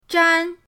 zhan1.mp3